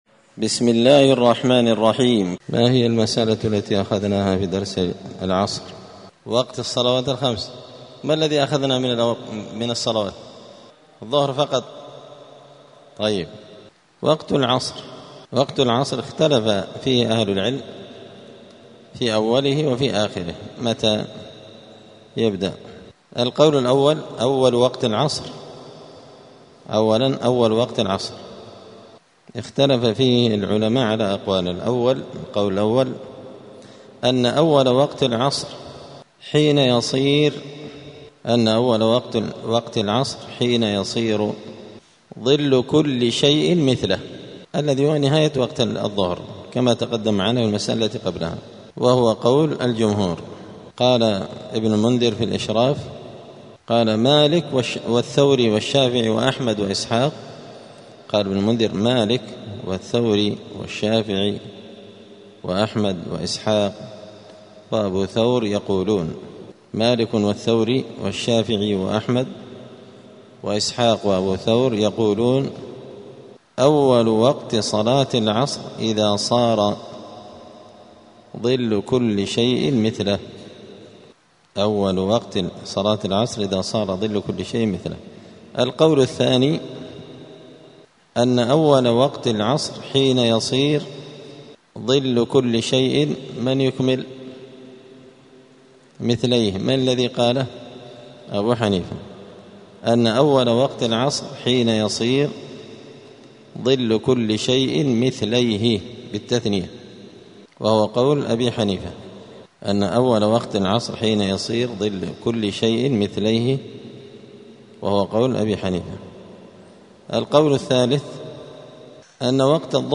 دار الحديث السلفية بمسجد الفرقان قشن المهرة اليمن
*الدرس السابع والعشرون بعد المائة [127] {وقت العصر}*